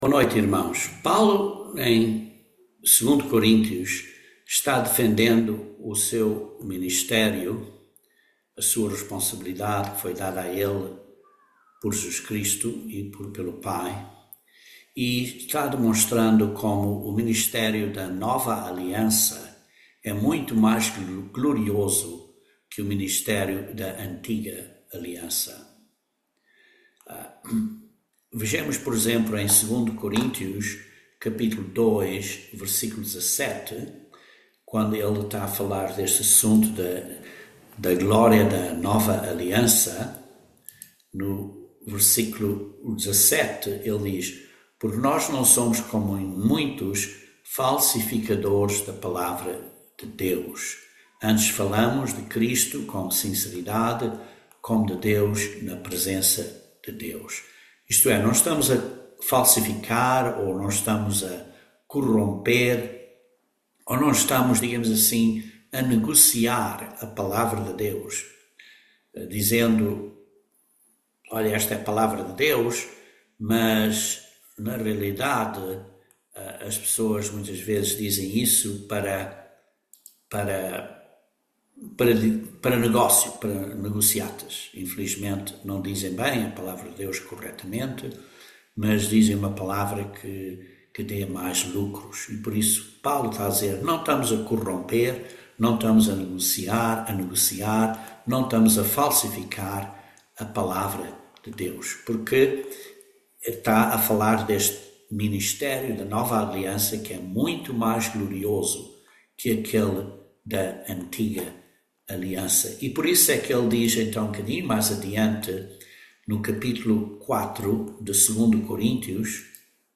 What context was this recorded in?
Given in Patos de Minas, MG